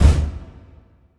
Kick SwaggedOut 11.wav